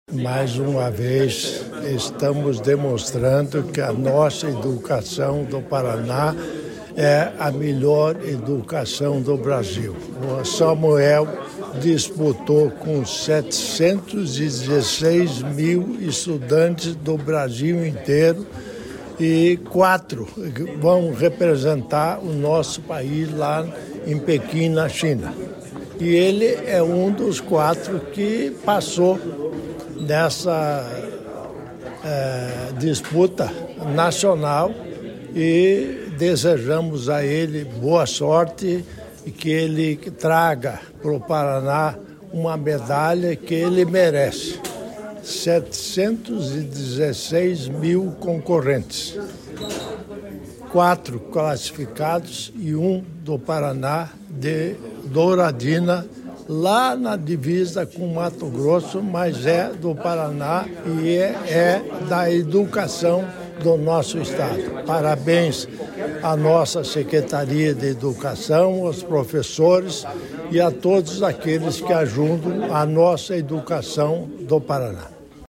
Sonora do vice-governador Darci Piana sobre estudante da rede estadual que participará da Olimpíada Internacional de Inteligência Artificial